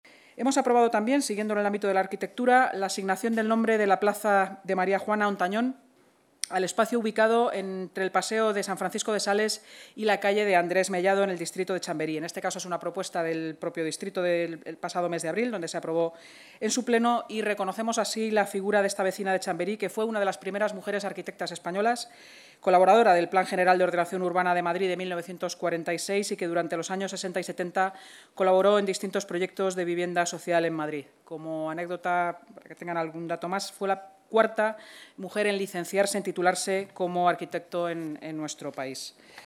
Nueva ventana:Así lo ha contado la vicealcaldesa y portavoz municipal, Inma Sanz, en rueda de prensa: